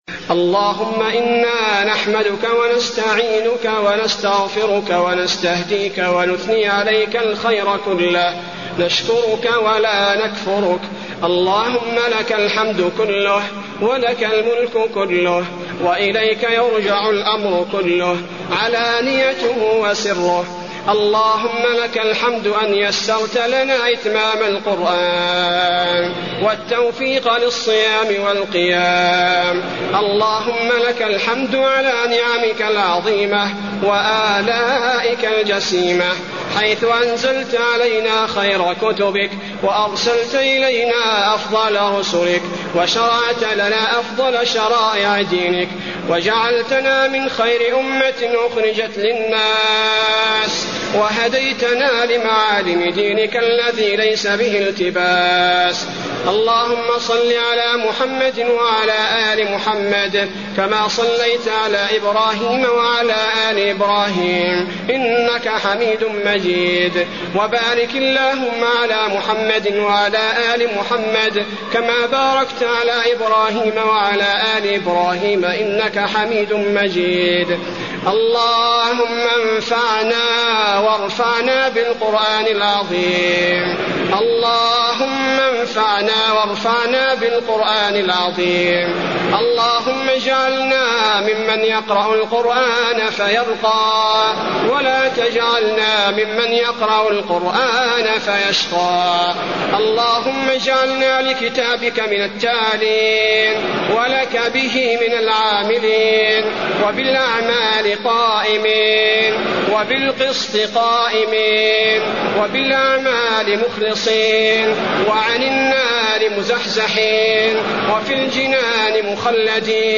دعاء ختم القرآن
المكان: المسجد النبوي دعاء ختم القرآن